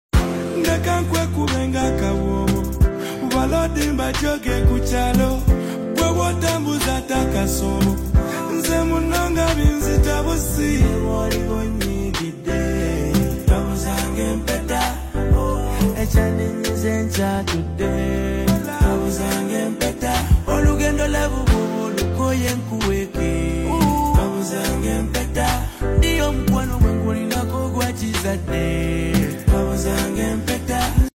With its catchy melodies infectious rhythm